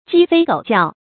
雞飛狗叫 注音： ㄐㄧ ㄈㄟ ㄍㄡˇ ㄐㄧㄠˋ 讀音讀法： 意思解釋： 把雞嚇得飛起來，把狗嚇得直叫喚。